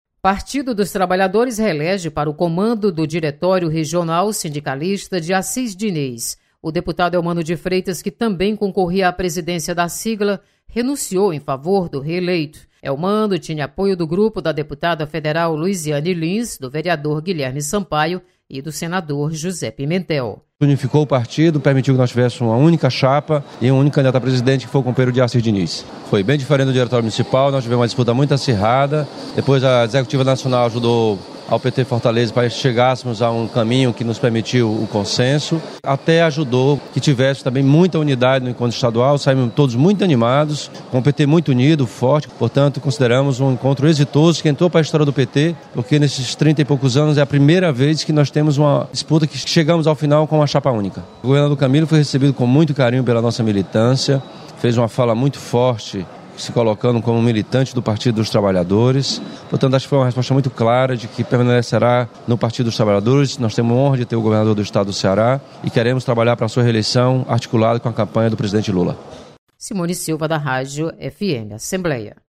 Deputado Elmano de Freitas comenta sobre eleições no PT.